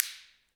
Index of /90_sSampleCDs/Roland L-CD701/PRC_Clap & Snap/PRC_Snaps